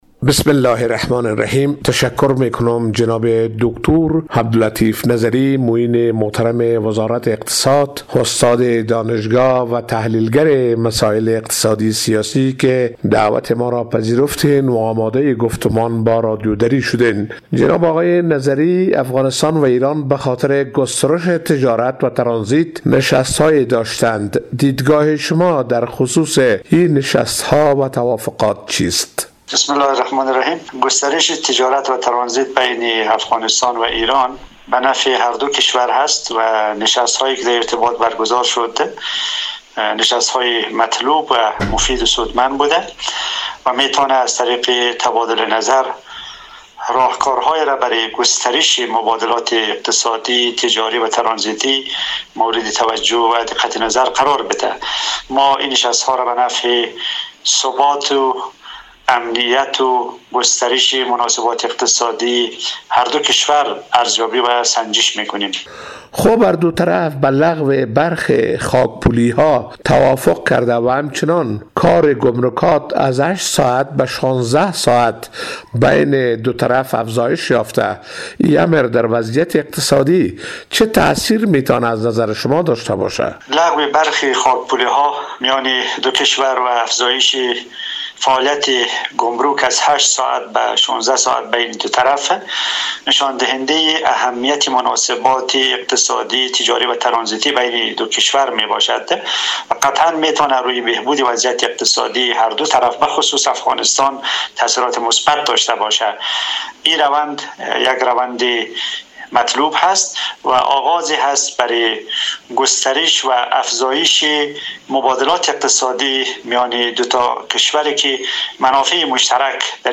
عبداللطیف نظری معاون وزارت اقتصاد حکومت طالبان در گفت وگوی اختصاصی با رادیو دری